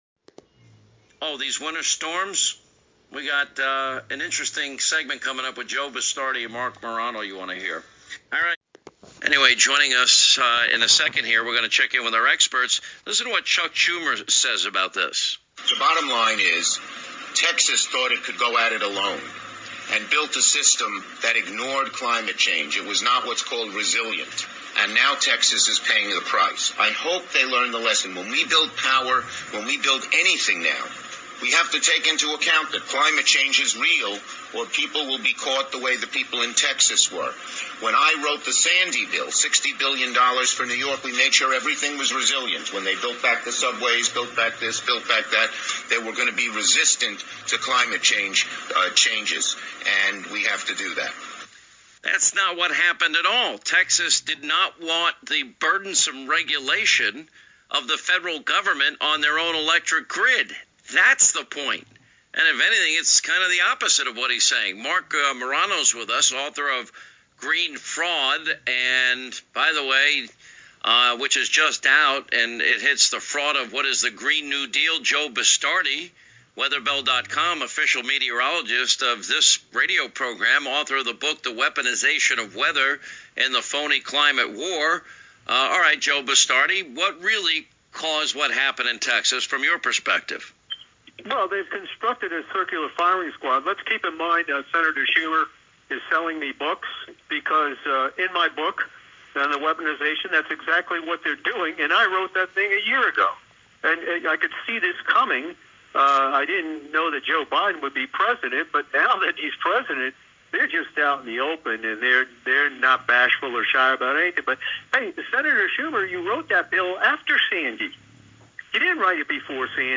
Listen: Morano on Hannity’s nationally syndicated radio show – Talks Texas energy, Green New Deal with Meteorologist Joe Bastardi